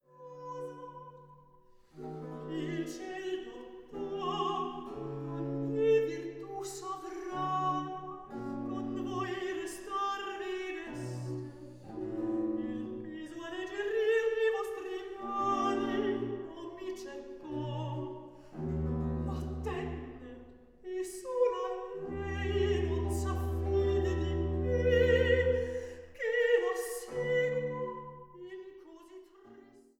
Sopran